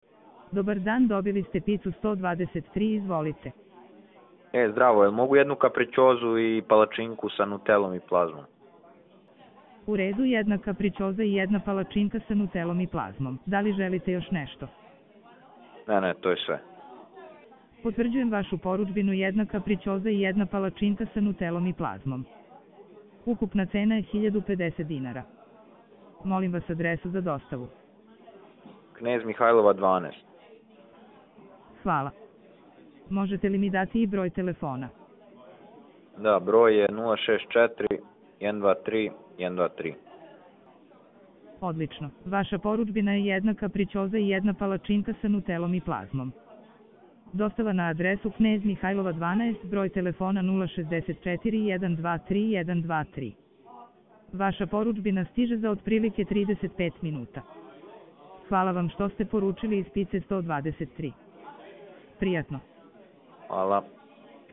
Ovo su demonstracije stvarnih scenarija u kojima AI agent razgovara kao pravi operater.